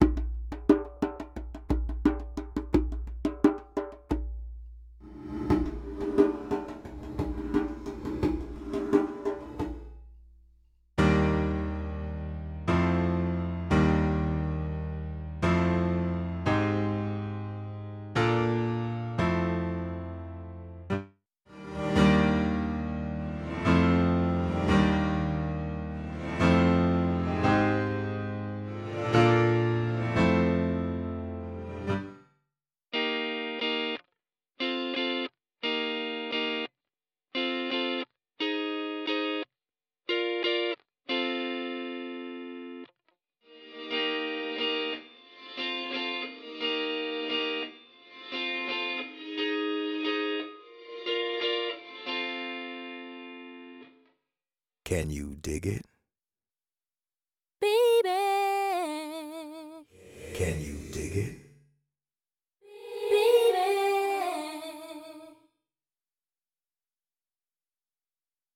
Реверберация типа Reverse:
Реверсивная реверберация создается путем проигрывания обработанно части сигнала задом-наперед. То есть, сначала мы слышим затухание реверберации, громкость которой постепенно возрастает - и в конце мы слышим оригинальную реверберационную атаку.
Reverse.mp3